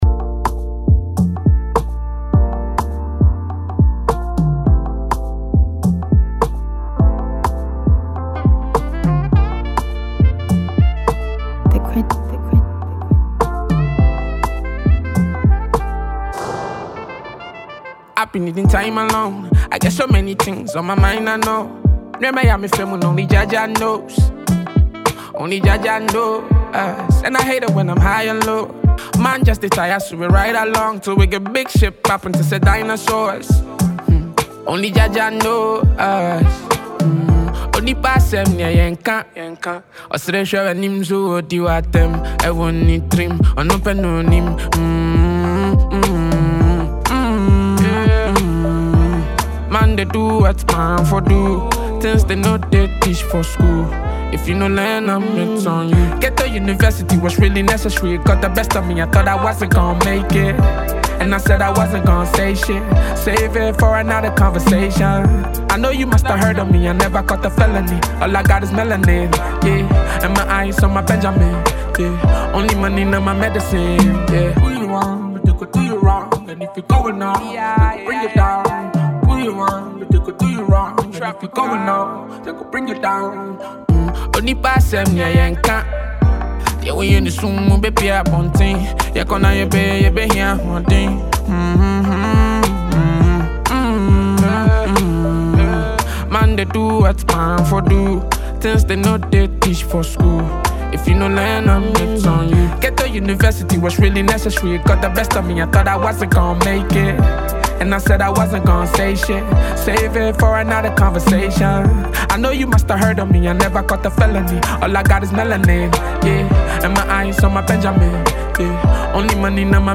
a free mp3 download Ghana afrobeat.
Enjoy this dope and melodious production.